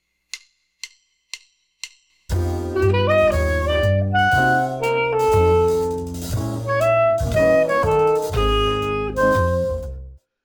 a-12-tone-non-row-first-key-only.mp3